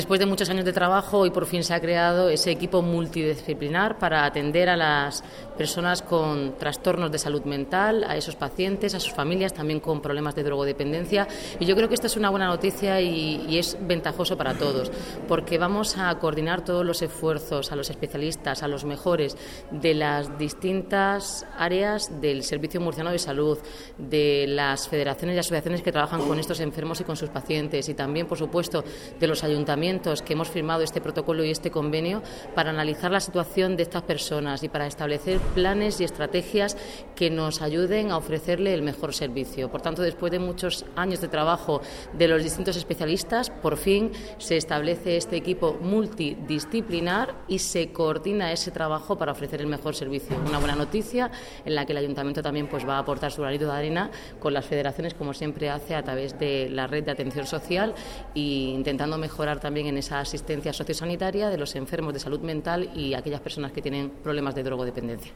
La vicealcaldesa y concejala de Bienestar Social, Noelia Arroyo y la concejala delegada de Servicios Sociales, Mercedes García, han asistido a la firma del Protocolo de atención socio-sanitaria en la atención a las personas con trastorno mental grave y/o drogodependencia, celebrado en la mañana del 30 de octubre, en el Salón de Actos del Hospital General Universitario Santa María del Rosell en Cartagena.